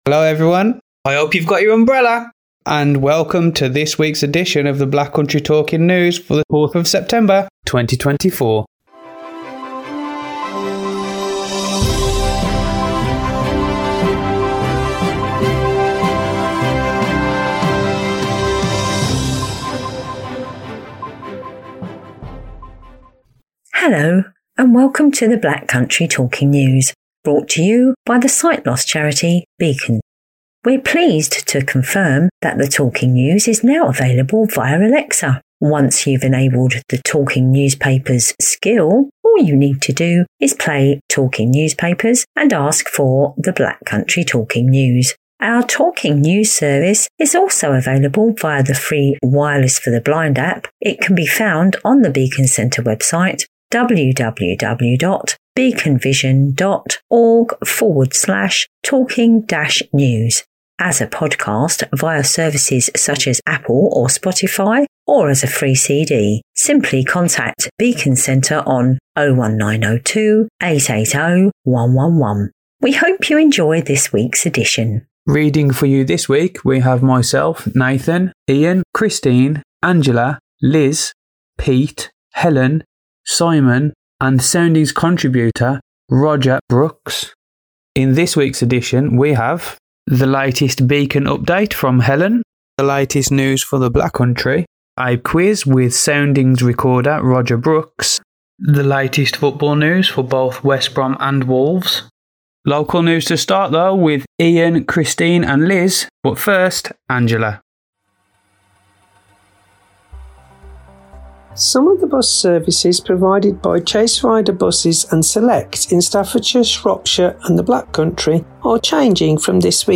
We have the latest news for the Black Country. We have all the reaction to this week's football action for West Brom and Wolves, in our new Fanzone feature! And with thanks to our friends at the Wildlife Sound Recording Society, we introduce a new type of recording this week offering an audio described wildlife spotting adventure.